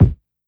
Medicated Kick 25.wav